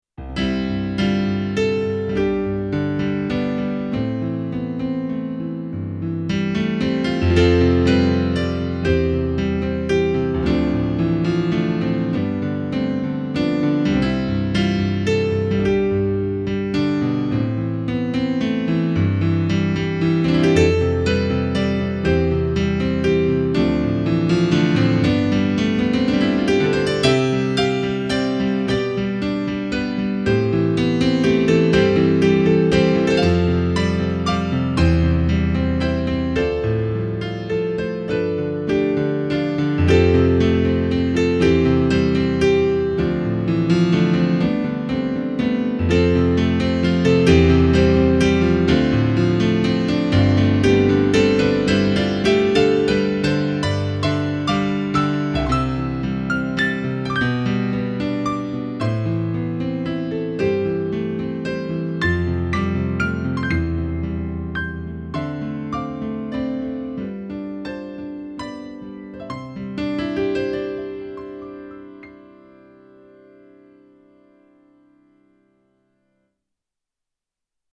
However, I’ve accented the melody notes (the highest notes) so that you will easily be able to pick them out from the backing chords and accompanyment. The example song is one I simply played within a couple of minutes of sitting at the piano, it could have turned out very differently depending on my mood, the day, the weather or whatever… but each version would all have one thing in common.